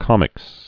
(kŏmĭks)